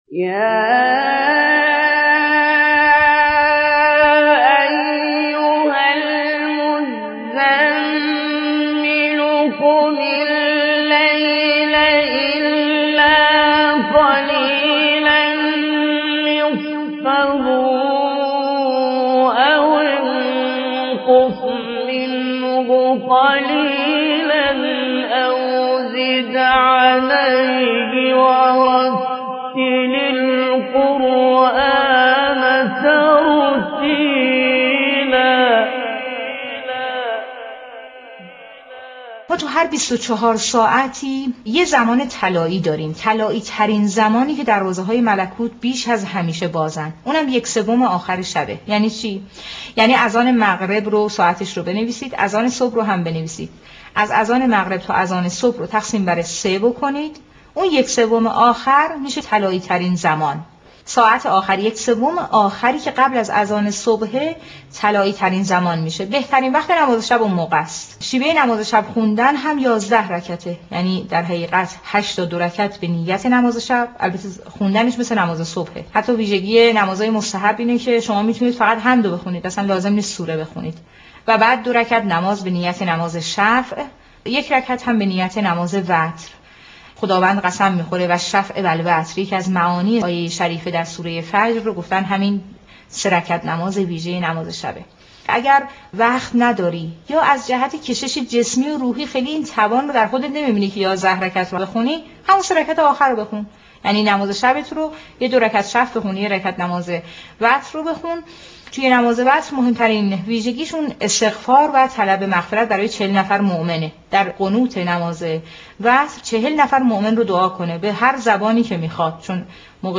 نواهنگ نماز (نماز شب)